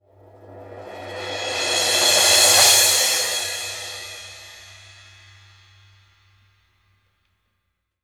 MALLETSO.1-R.wav